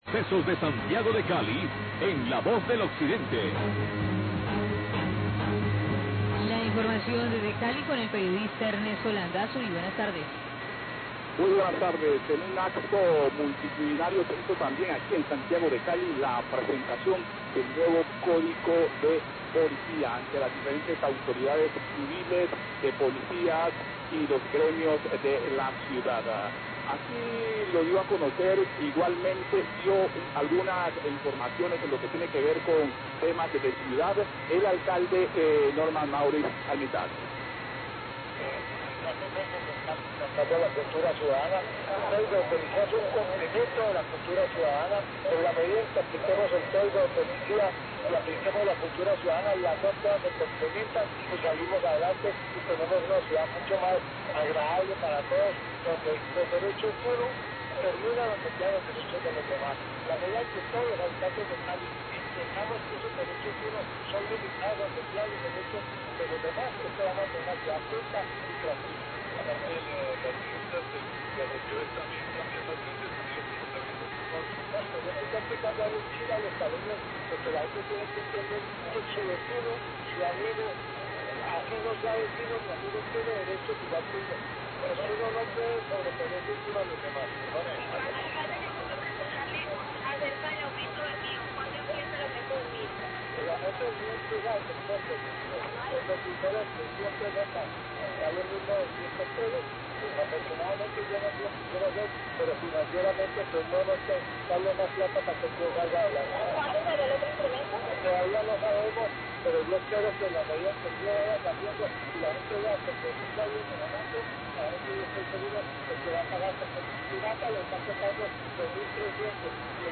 Radio
En un acto multitudinario se cumplió la presentación del nuevo código de Policía en Santiago de Cali, ante las diferentes entidades civiles, de Policía y los gremios de la ciudad. El alcalde de la capital vallecaucana, Maurice Armitage, se refiere a esta nueva medida.